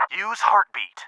tm_heart.wav